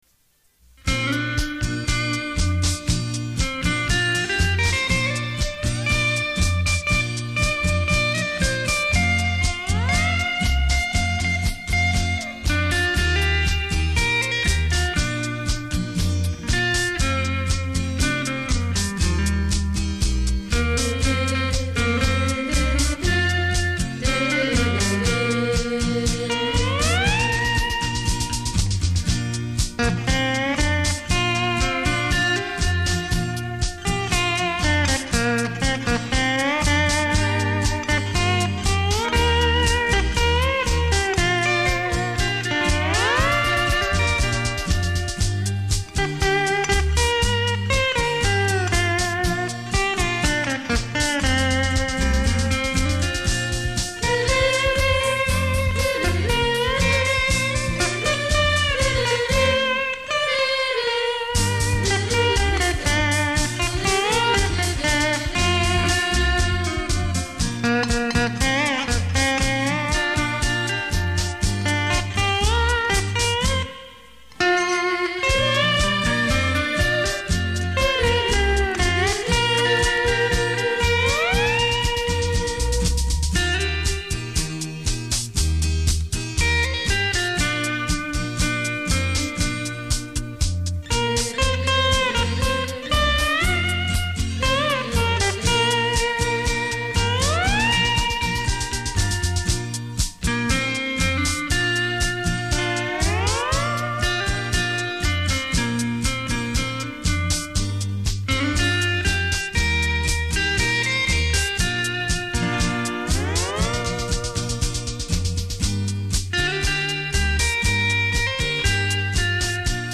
正宗夏威夷吉他演繹 音色華麗優美動聽
鄉村流行音樂不可缺少之利器
電聲發燒珍品·值得您精心收藏·細細聆賞...